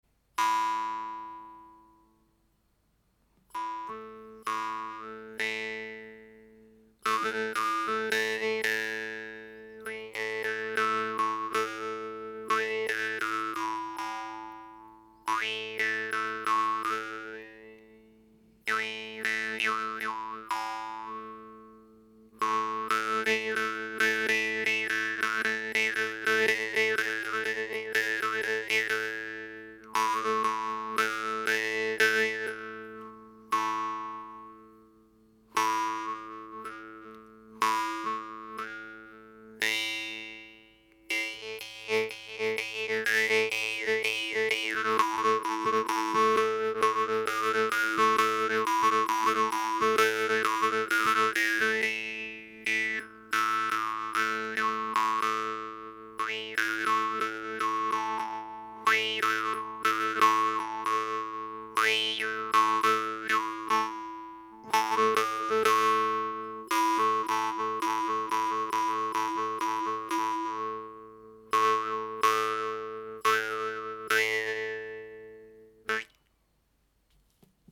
Guimbarde norvégienne de type Telemark, à cercle plat. Les MUNNHARPE TELEMARK ont une languette légèrement plus souple, sont un peu plus courtes et produisent un son plus doux que les MUNNARPE SETESDAL, ce qui les rend idéales pour jouer de belles mélodies.